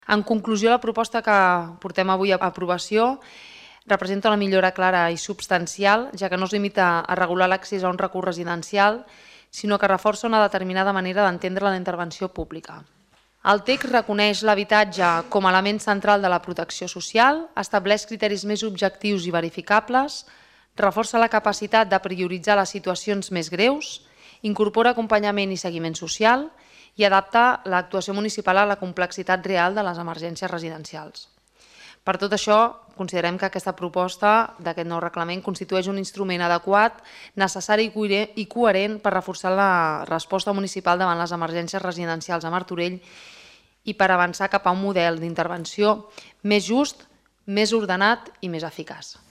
Cristina Dalmau, regidora Benestar Social
Ple-Abril-04.-Cristina-Dalmau.mp3